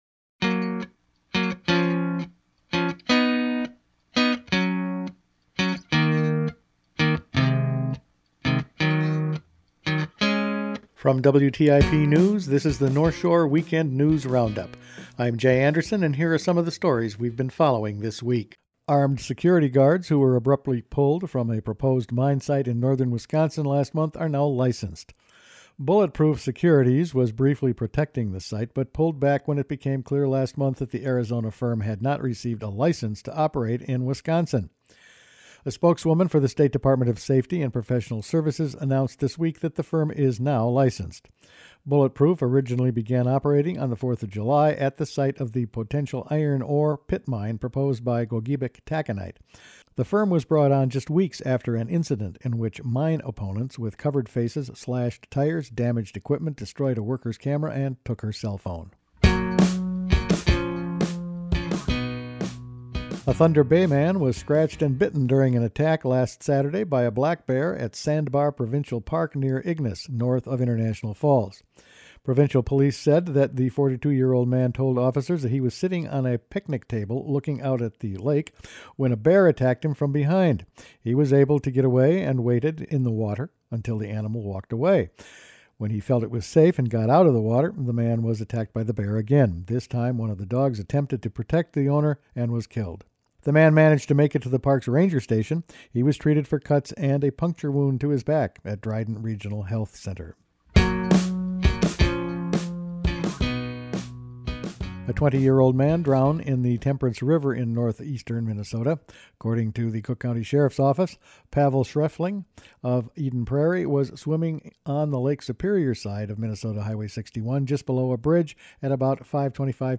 Weekend News Roundup for August 10